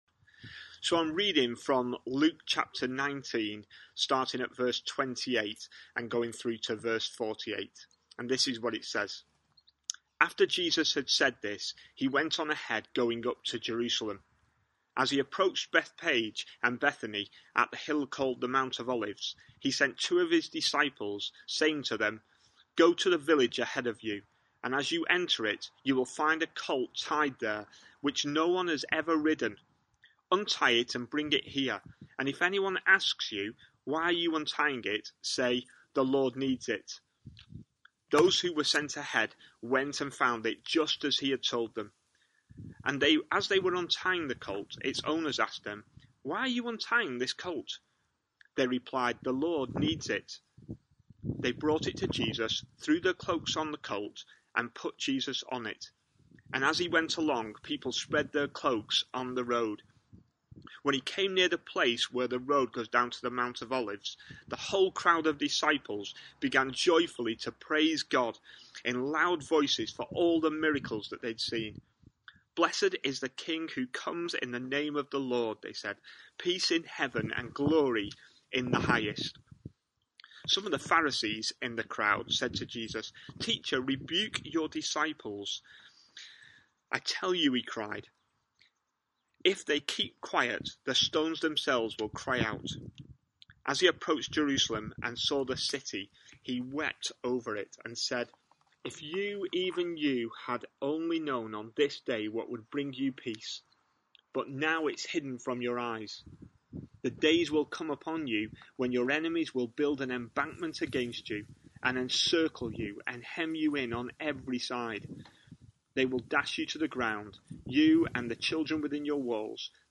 A sermon preached on 5th April, 2020.